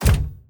train-door-close-1.ogg